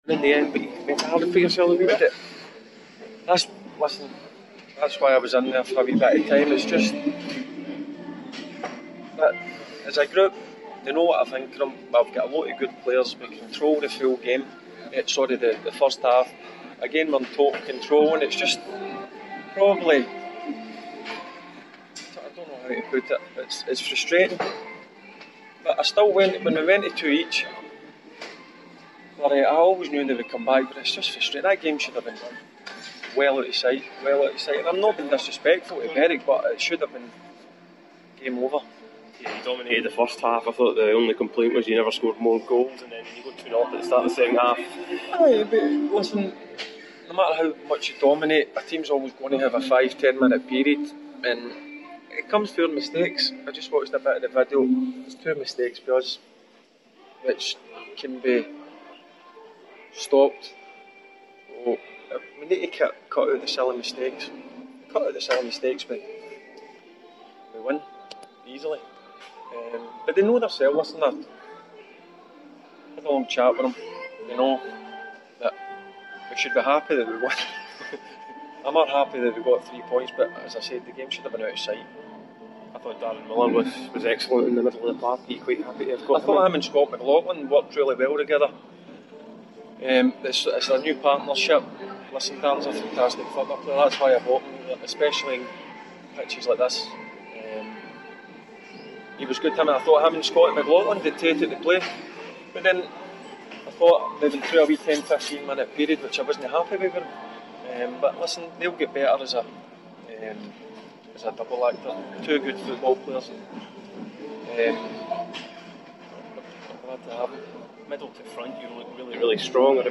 Barry Ferguson's press conference after the Ladbrokes League 2 match.